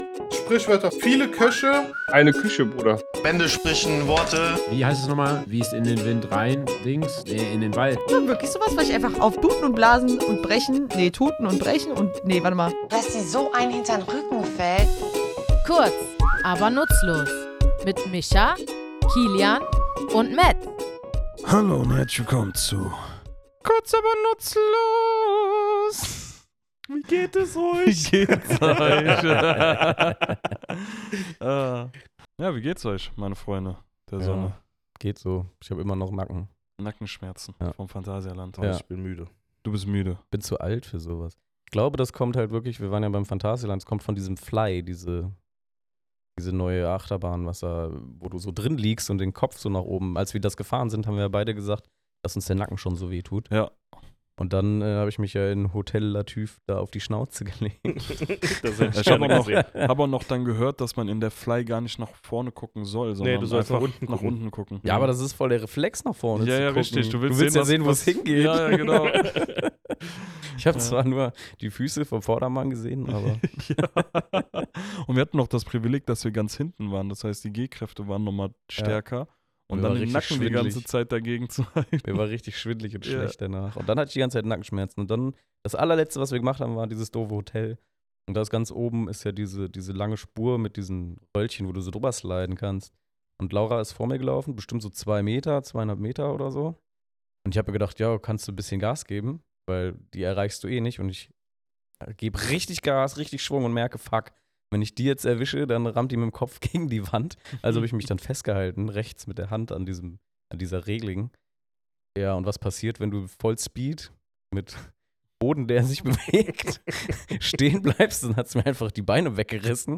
Wir, drei tätowierende Sprachnerds, plaudern in unserem Tattoostudio über skurrile, schräge und überraschende Fun Facts, die vielleicht niemand braucht – aber jeder gern erzählt.